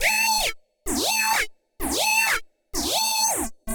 Index of /musicradar/uk-garage-samples/128bpm Lines n Loops/Synths